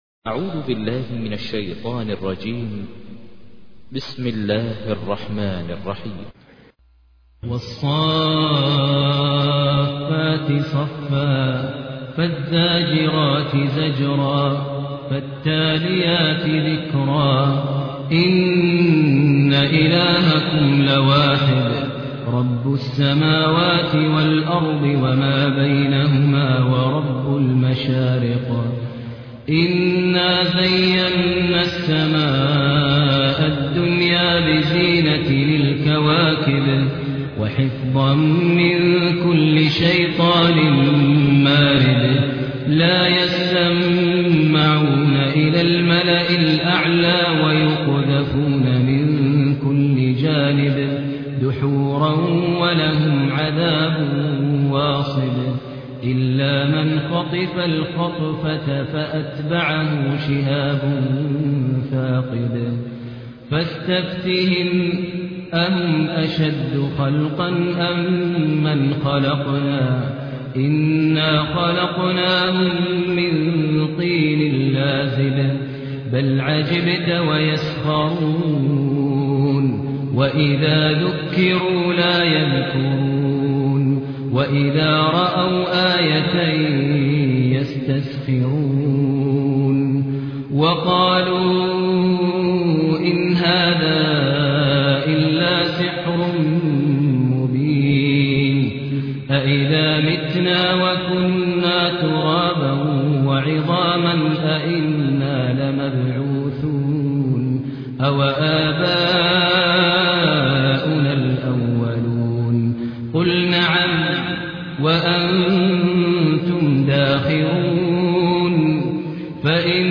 تحميل : 37. سورة الصافات / القارئ ماهر المعيقلي / القرآن الكريم / موقع يا حسين